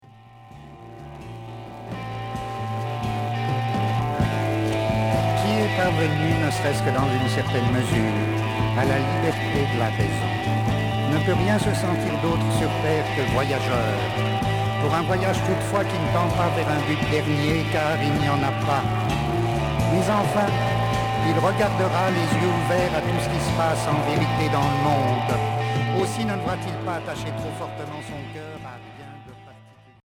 Expérimental Deuxième 45t retour à l'accueil